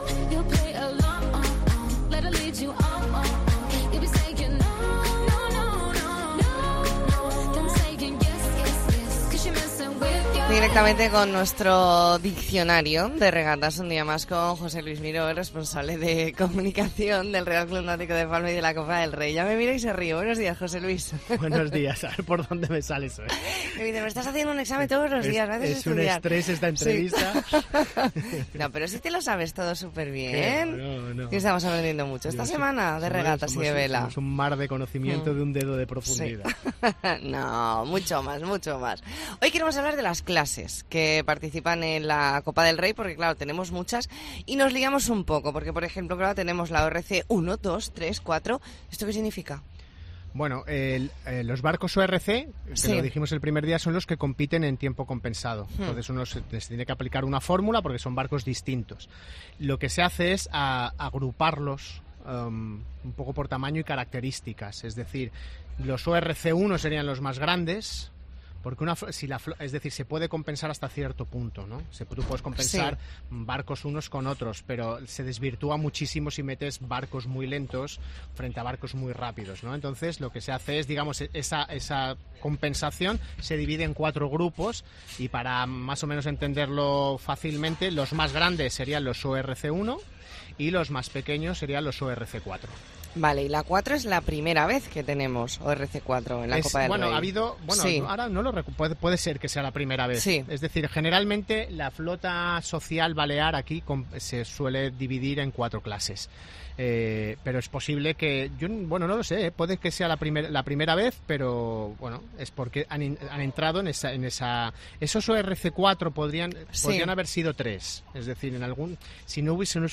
Especial La Mañana en COPE Más Mallorca desde el RCNP con motivo de la 40 Copa del Rey Mapfre